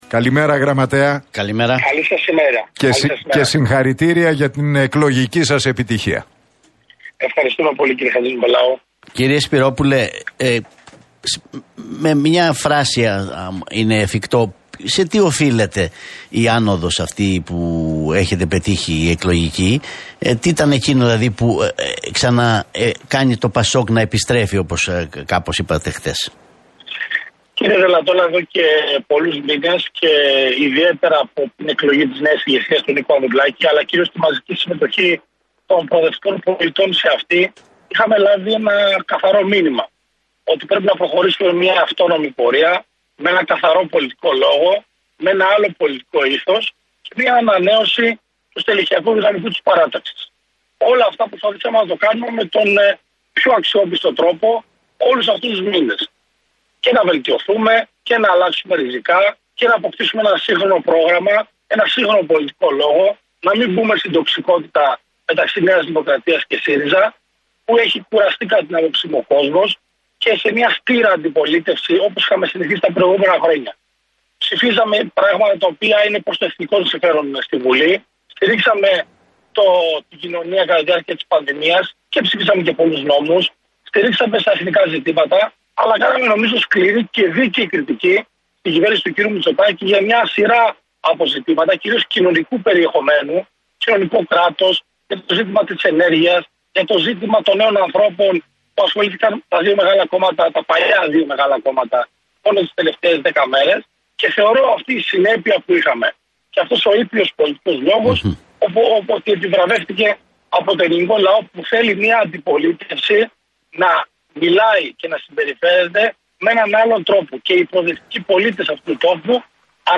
σχολίασε το αποτέλεσμα της κάλπης των εκλογών, μιλώντας στον Realfm 97,8